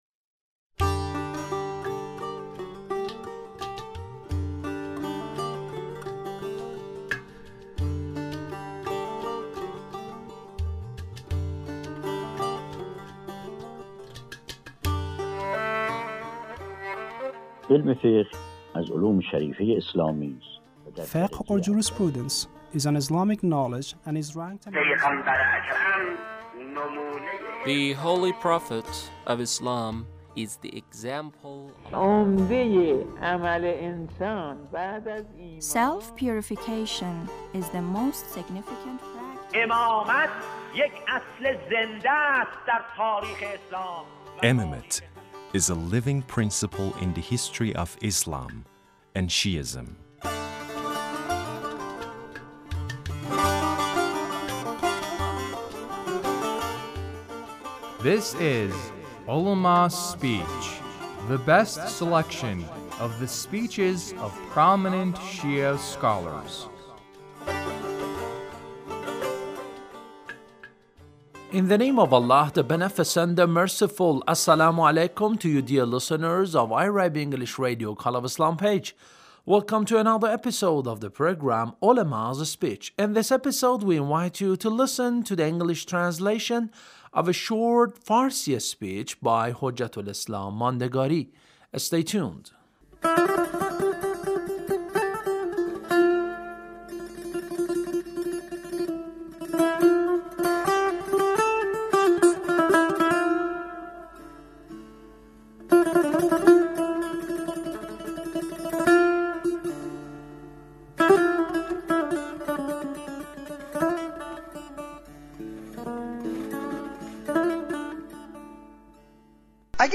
Ulemas' Speech (1243)